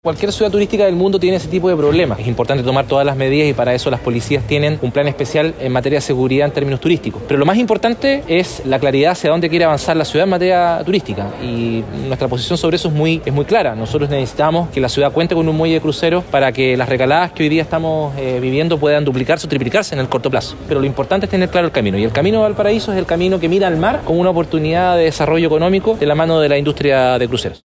Consultado precisamente por materias de seguridad, el alcalde de Valparaíso, Jorge Sharp, apuntó a un plan especial en términos turísticos gestionado por las instituciones policiales. Asimismo, remarcó lo importante que sería para la ciudad porteña un “muelle de cruceros”.